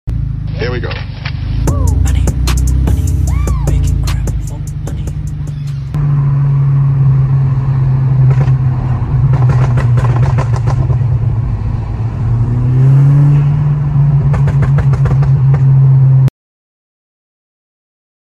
VW Golf MK6 GTI > Pops sound effects free download
VW Golf MK6 GTI--> Pops and bangs tune Customer brought in their modified GTI in for pops and bangs, within a couple hours the car was ready to hand back to the customer. The customer is satisfied with how loud we were able to tune the car as well as the quick service.